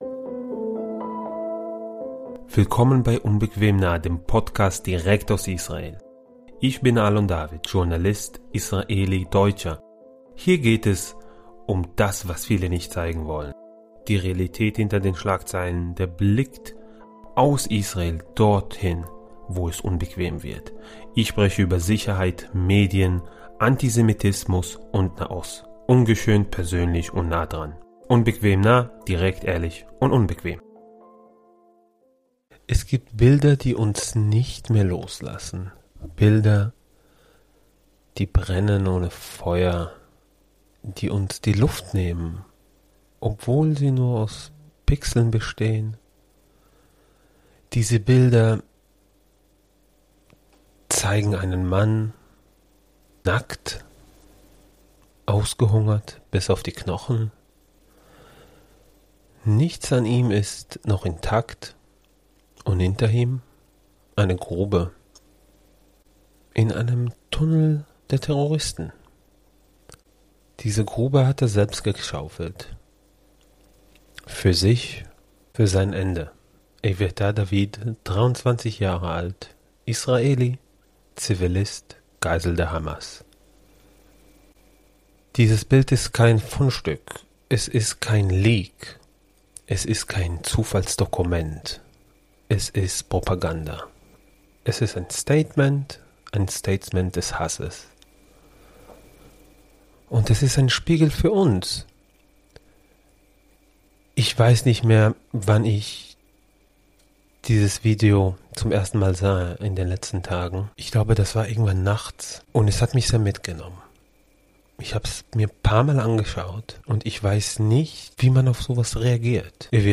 Diese Folge ist ein eindringlicher Monolog über ein Symbol jüdischen Leidens, über selektives Mitgefühl, über Hamas-Propaganda, Menschenwürde - und die Verantwortung, nicht wegzusehen.